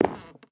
sounds / step / wood6.ogg
wood6.ogg